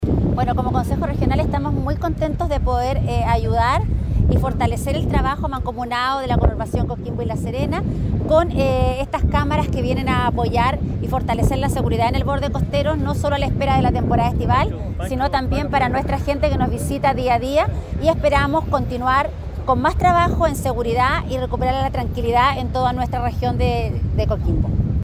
CORE-PAOLA-CORTES.mp3